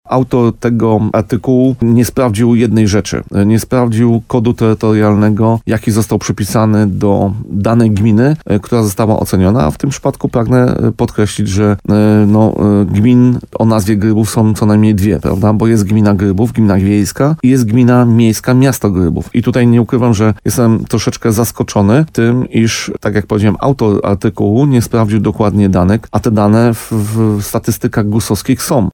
– Ta informacja to wprowadzanie naszych mieszkańców w błąd – powiedział w programie Słowo za Słowo w radiu RDN Nowy Sącz Paweł Fyda.